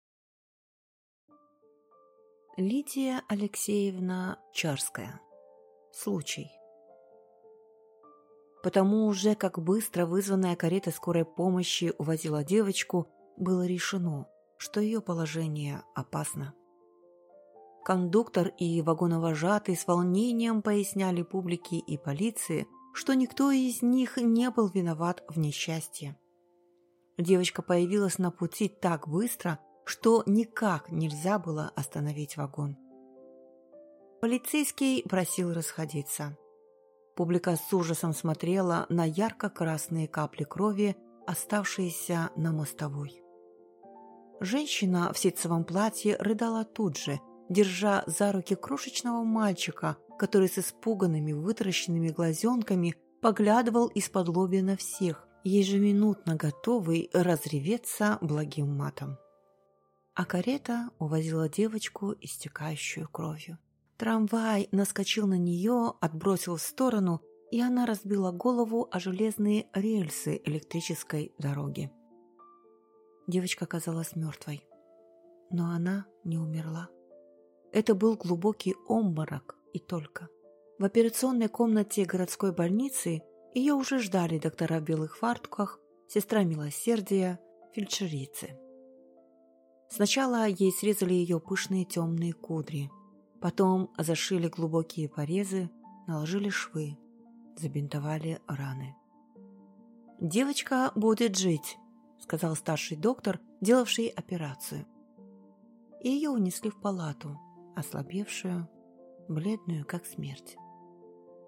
Аудиокнига Случай | Библиотека аудиокниг